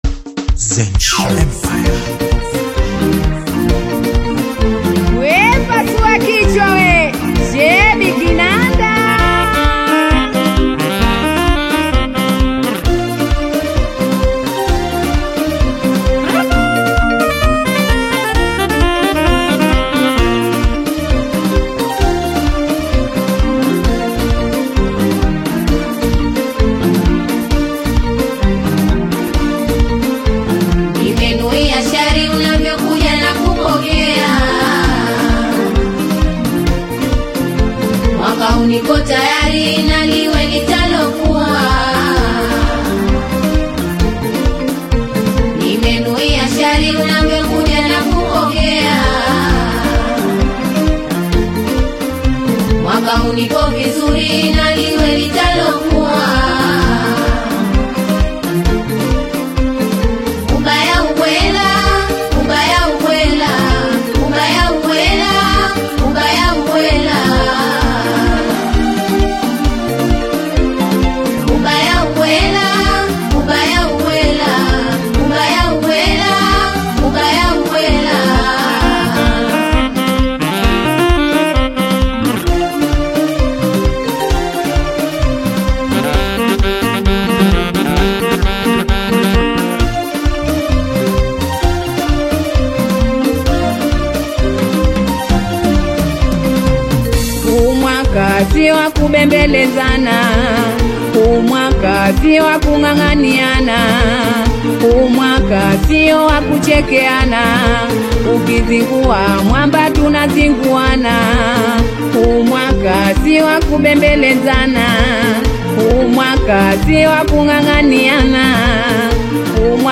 spirited Afro-Fusion/Taarab-inspired single
Genre: Taarab